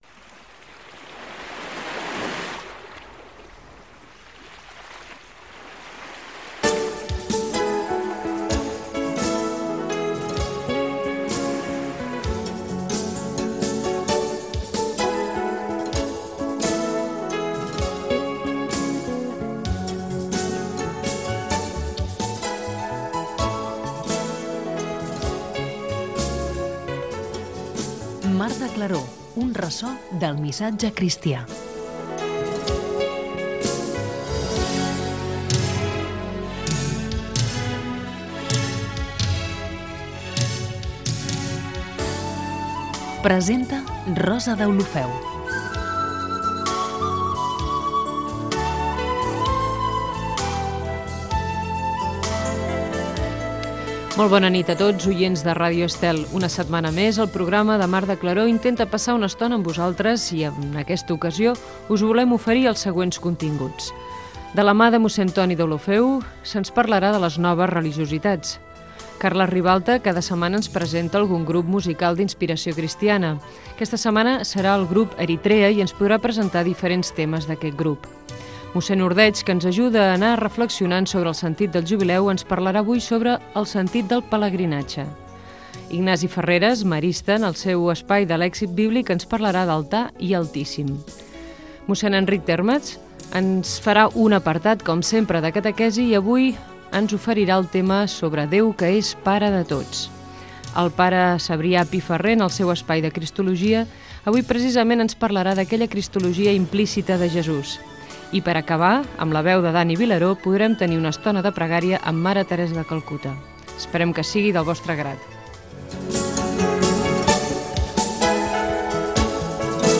Religió
FM